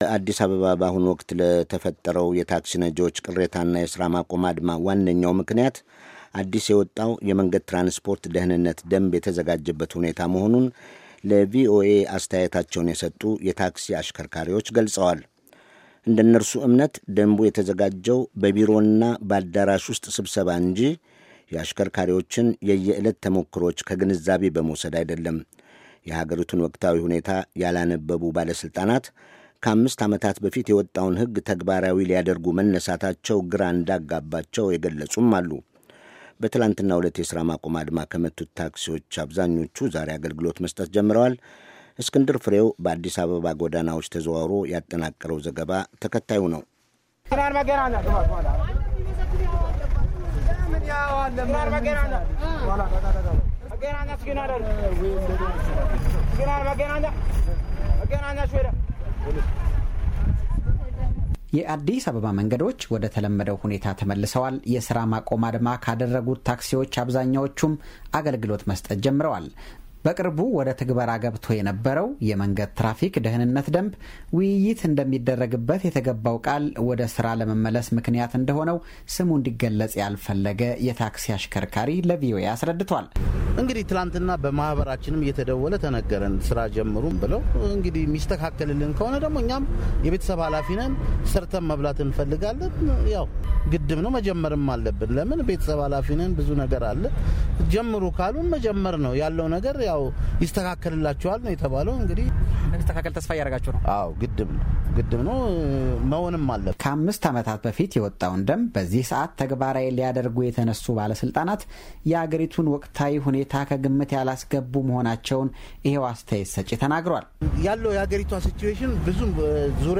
በአዲስ አበባ ጎዳናዎች ተዘዋውሮ ያጠናቀረው ዘገባ